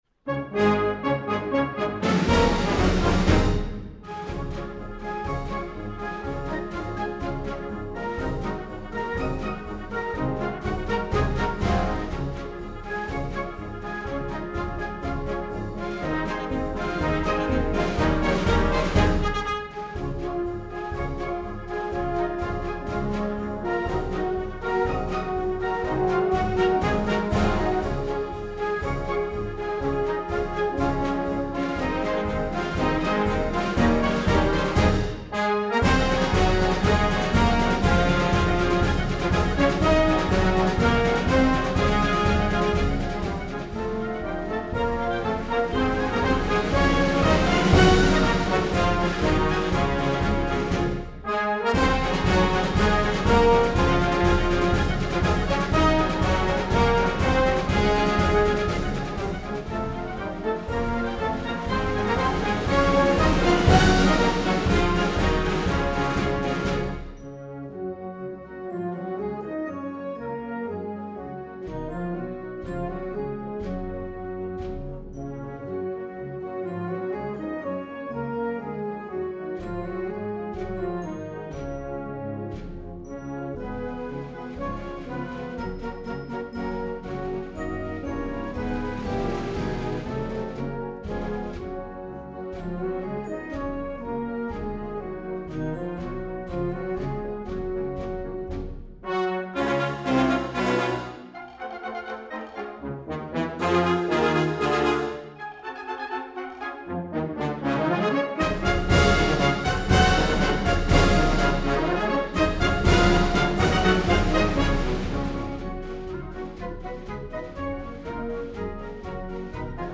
March